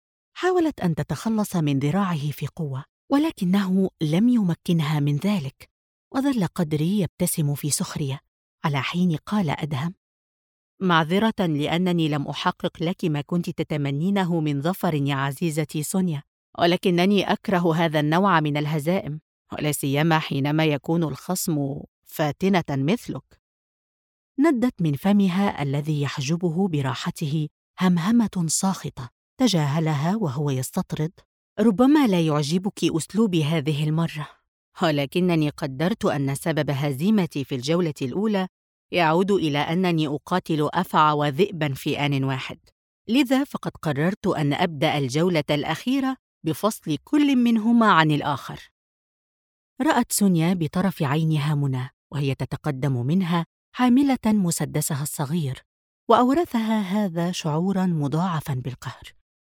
Audiolibros
Micrófono: Rode NT1-A
Estudio: Estudio casero con tratamiento profesional para una acústica óptima